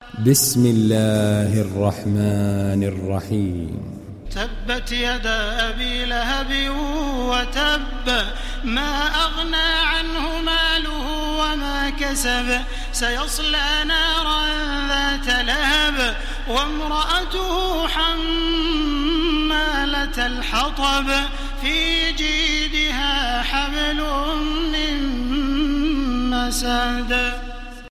Télécharger Sourate Al Masad Taraweeh Makkah 1430